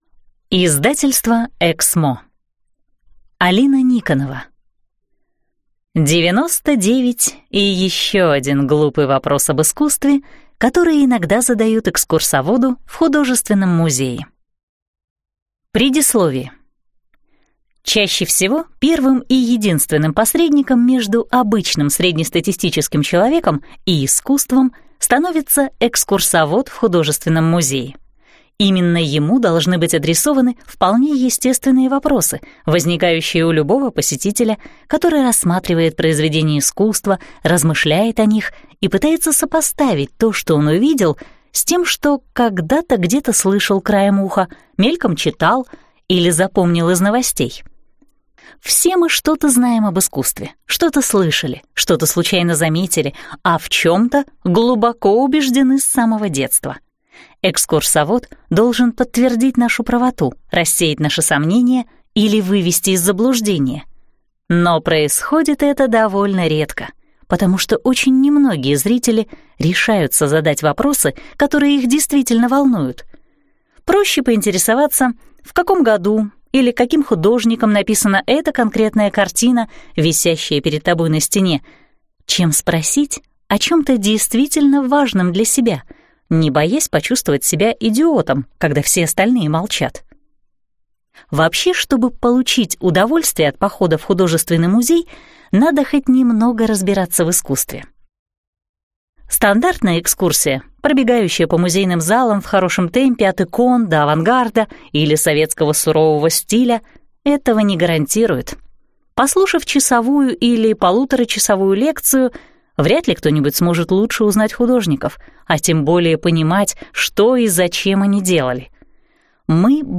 Аудиокнига 99 и еще один глупый вопрос об искусстве. Вопросы, которые вы не решались задать | Библиотека аудиокниг